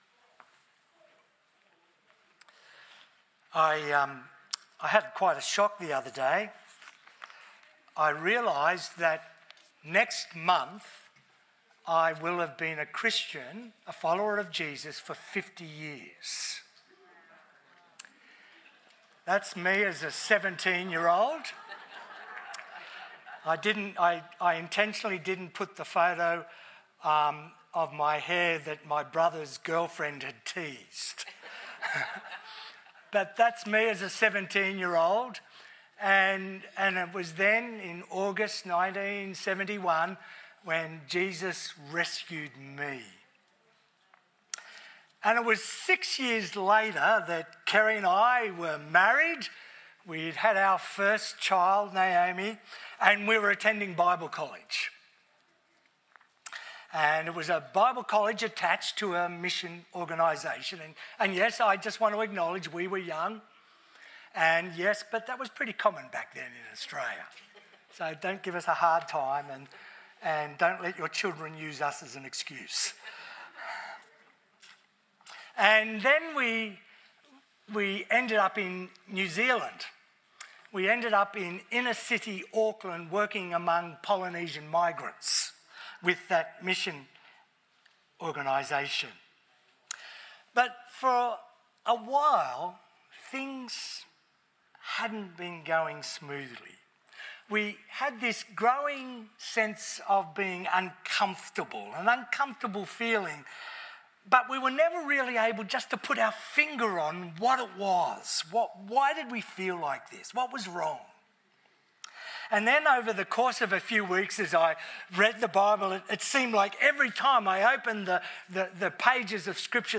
Categories Sermon Leave a Reply Cancel reply Your email address will not be published.